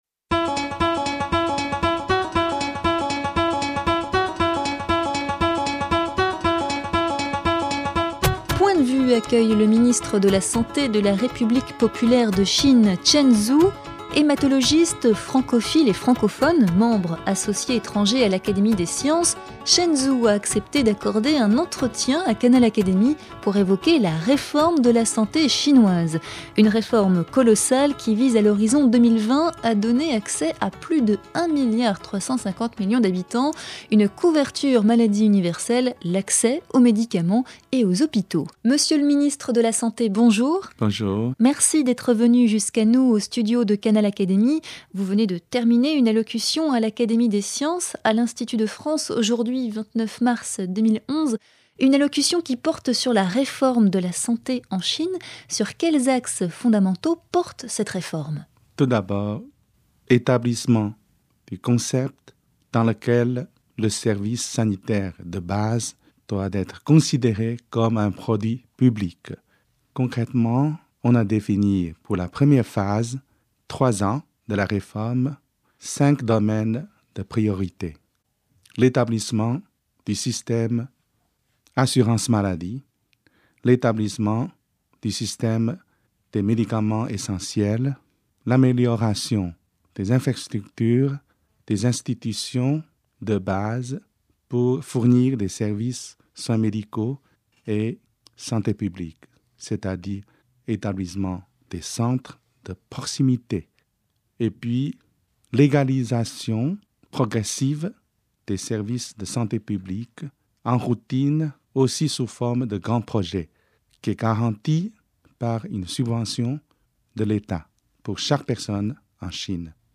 Entretien exclusif avec Chen Zhu, Ministre de la santé de la République populaire de Chine, membre associé étranger de l’Académie des sciences
Accueilli par le président de Canal Académie Jean Cluzel et accompagné par Catherine Bréchignac, Secrétaire perpétuel de l'Académie des sciences, le Ministre de la santé Chen Zhu a accepté de s'exprimer au micro de Canal Académie.
Francophone, le professeur Chen Zhu s'exprime en français dans cet entretien.